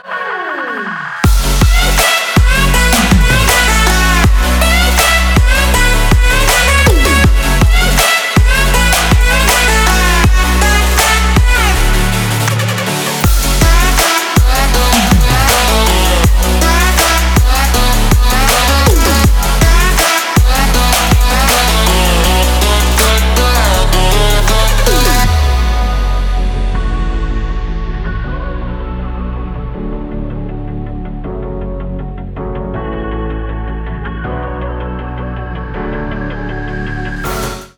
dance
Electronic
club
future bass
Прикольная клубная музыка